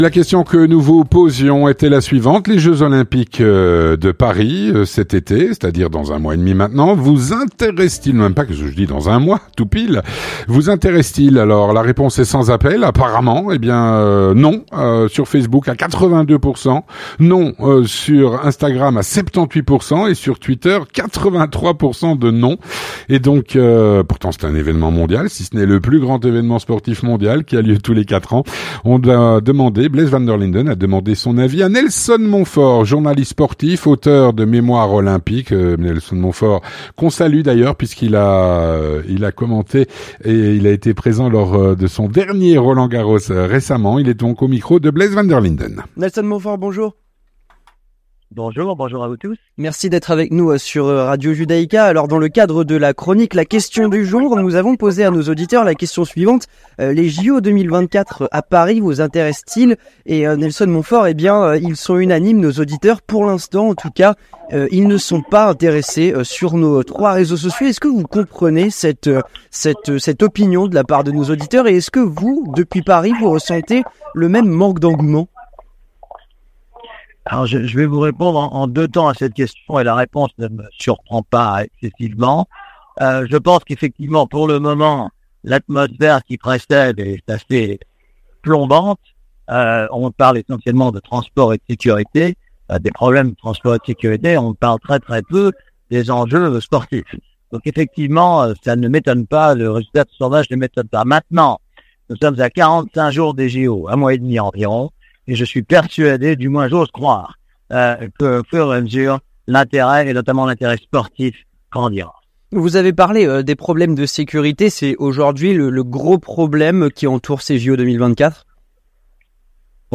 Avec Nelson Montfort, journaliste sportif, auteur de "Mémoires olympiques".